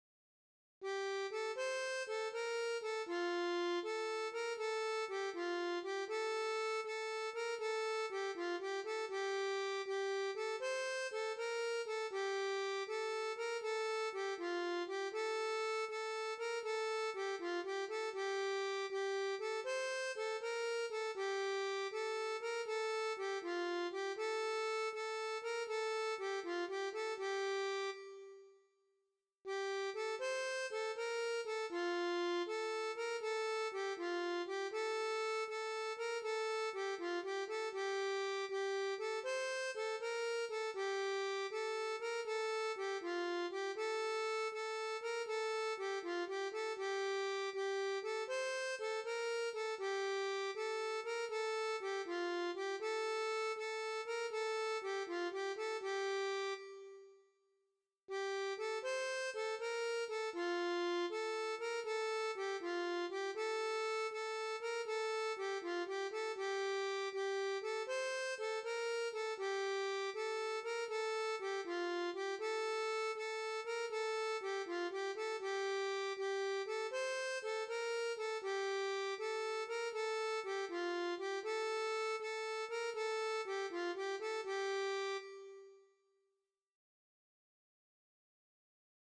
La m�lodie.
(Chant populaire du Moyen Age) Compositeur : Anonyme Chant Traditionnel FAILED (the browser should render some flash content here, not this text).